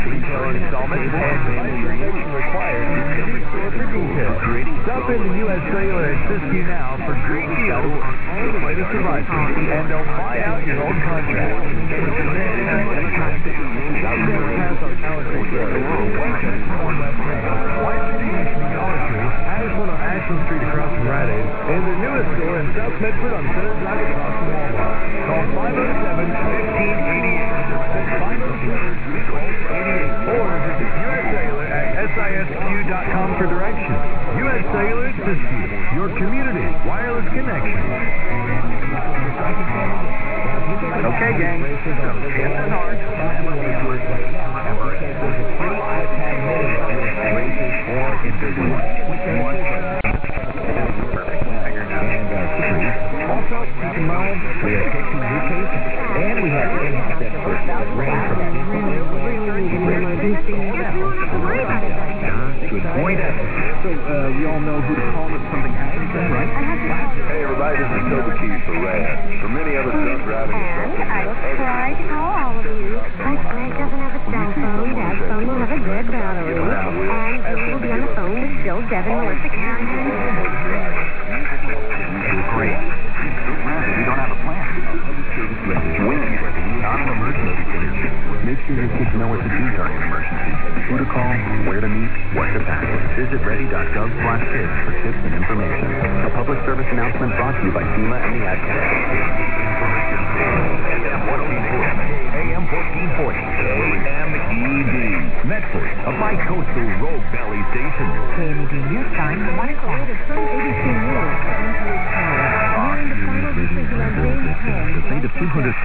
[IRCA] Kiribati on 1440 from near Florence, OR
I was using a 160â DKAZ with 902 ohms Rt [termination resistance] pointing about 235 degrees, pictured here:
At 0800utc on July 30th I heard the following unmistakable island choral version of âOh What A Friend We Have In Jesusâ under KMED - starts at :14.